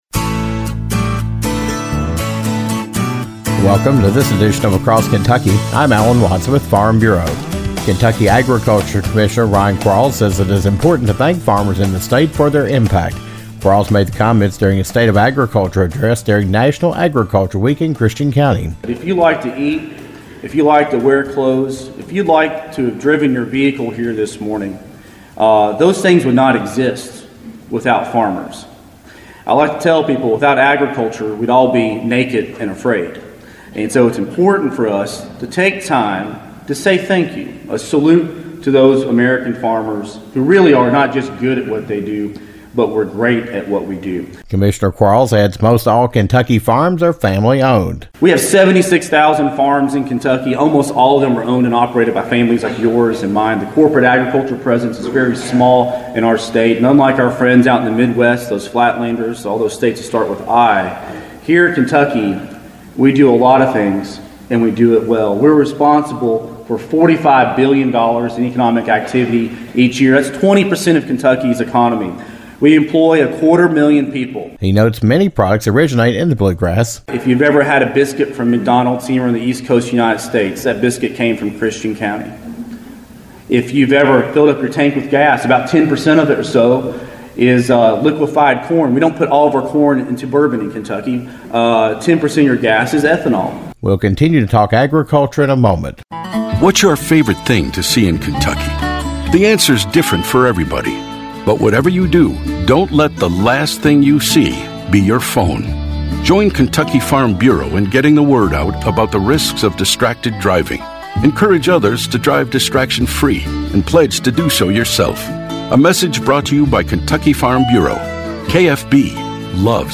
Kentucky Agriculture Commissioner Ryan Quarles says it is important to thank a farmer during National Agriculture Week, but also throughout the year.  Quarles talked about the impact of agriculture, the number of family farms in the state, specialty markets and how Kentucky ranks on the national level during a State of Agriculture address on National Agriculture Day.